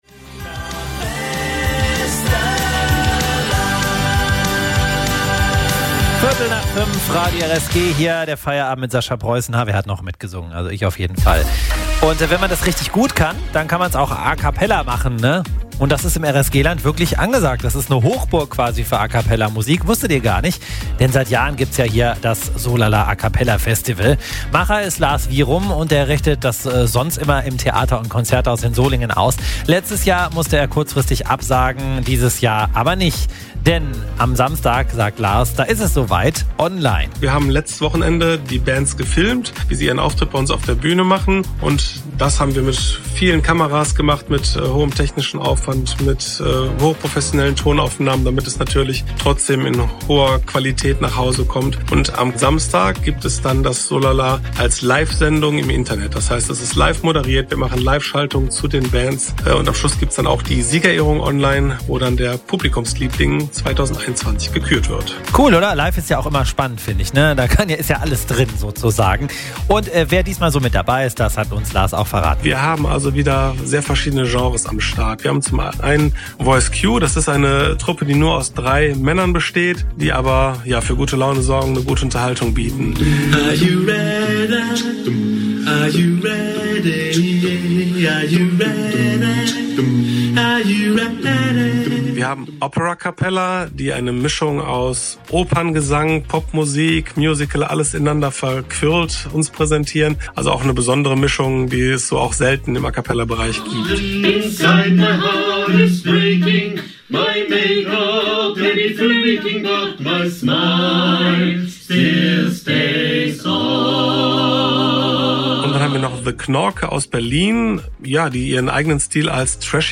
Heute gab es einen schönen Vorbericht bei Radio RSG!